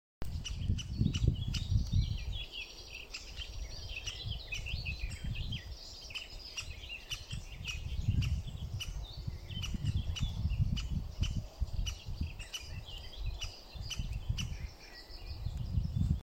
большой пестрый дятел, Dendrocopos major
СтатусПара в подходящем для гнездования биотопе